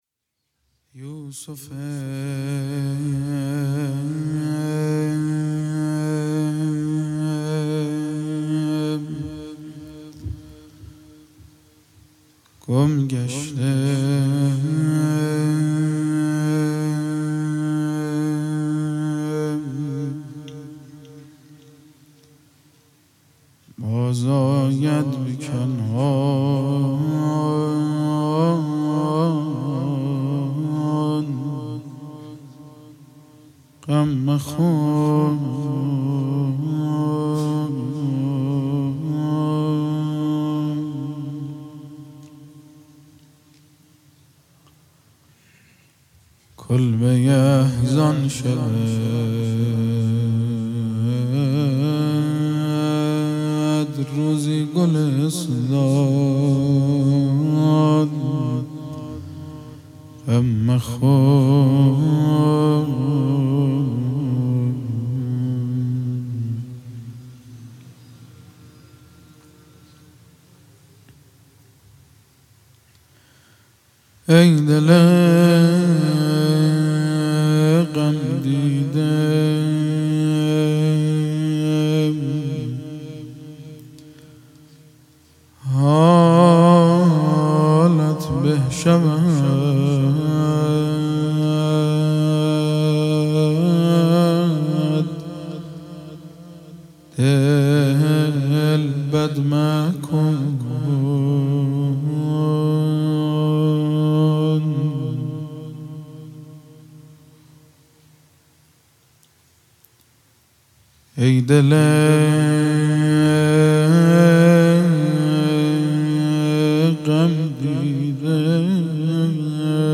یوسف گمگشته باز آید به کنعان غم مخور | مناجات و روضه ی حضرت علی اصغر علیه السلام